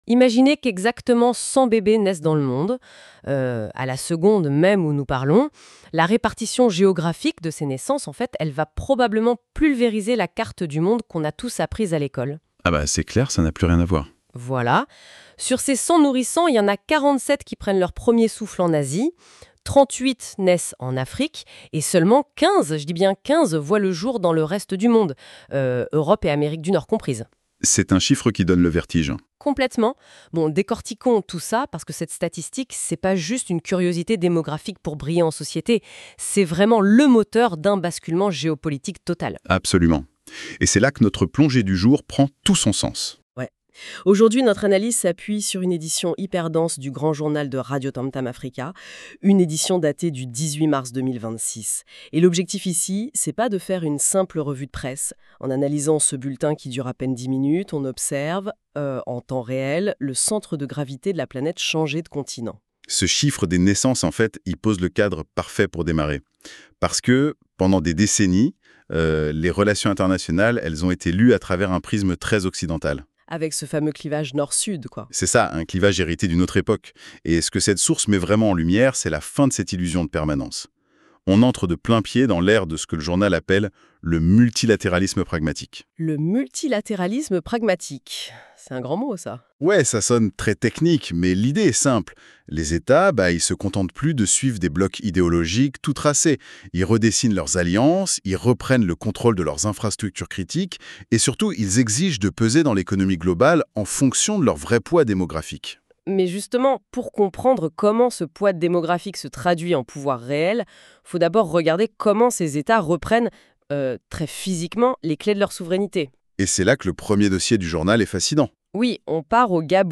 Depuis Bezons, aux portes de Paris,vous écoutez RADIOTAMTAM AFRICA.